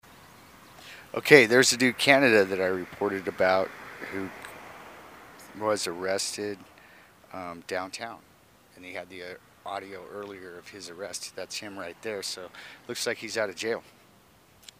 Live audio: Missoula cops cite beggar
The two officers involved in the sting were riding motorcycles.